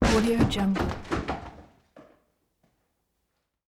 دانلود افکت صدای کوبیدن درب چوبی به هم 5
Sample rate 16-Bit Stereo, 44.1 kHz
Looped No